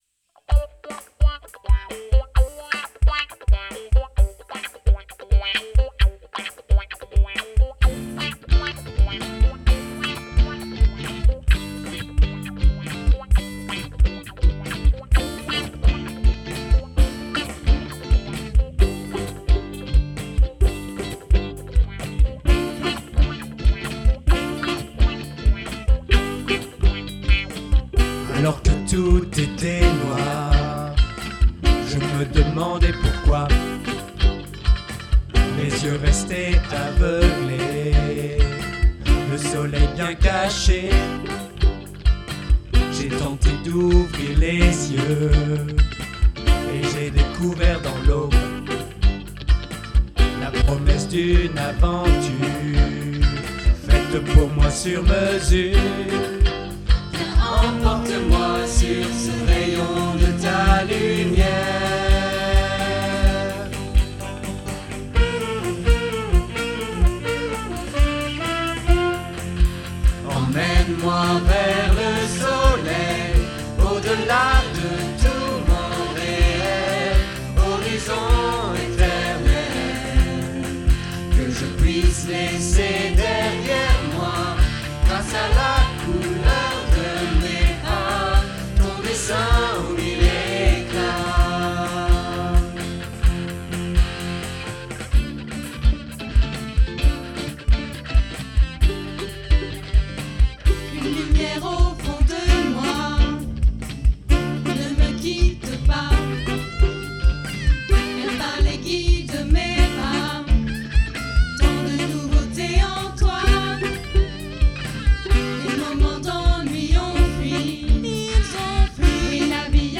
Enregistré lors du week-end unison : ./Sur mesure pour moi.mp3